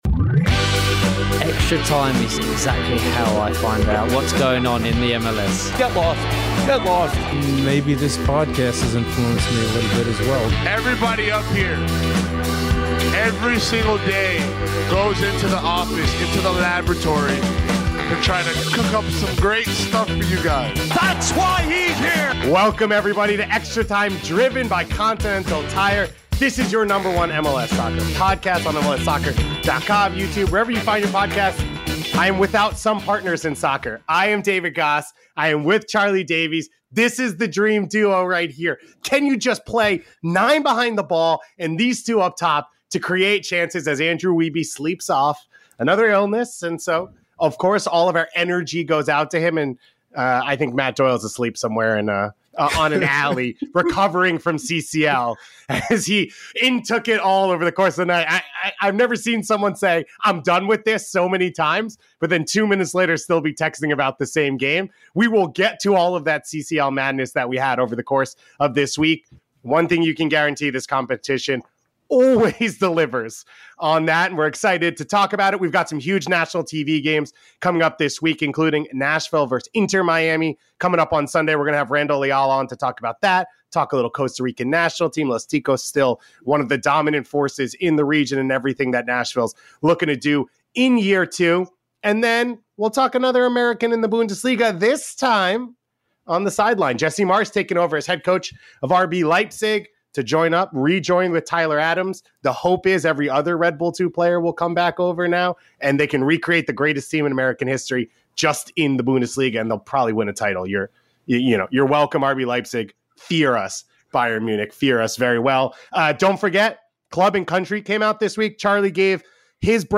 The guys do their best to break down the midweek CCL madness and figure out which MLS teams have the best shot to advance to the semifinals. Then we get you ready for the big games of the weekend, including an interview with Nashville SC’s Randall Leal ahead of Sunday’s matchup with Inter Miami. After that, we take a skip across the pond to discuss Jesse Marsch being named RB Leipzig manager and the latest developments in the Daryl Dike loan saga.